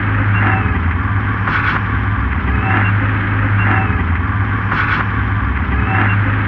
074 Engine.wav